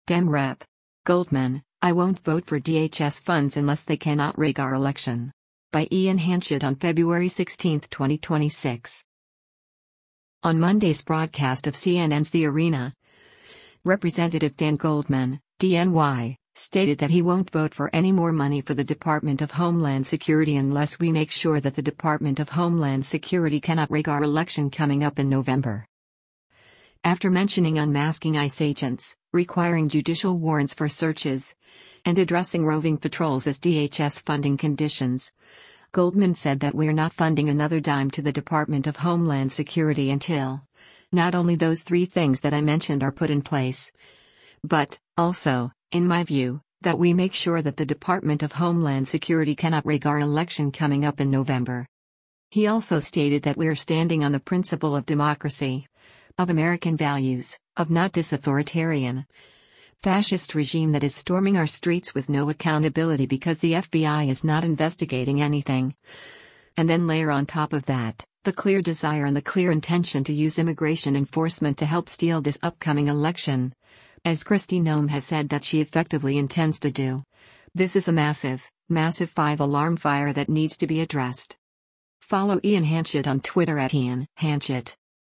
On Monday’s broadcast of CNN’s “The Arena,” Rep. Dan Goldman (D-NY) stated that he won’t vote for any more money for the Department of Homeland Security unless “we make sure that the Department of Homeland Security cannot rig our election coming up in November.”